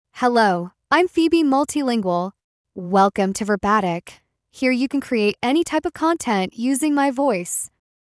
Phoebe Multilingual is a female AI voice for English (United States).
Voice sample
Female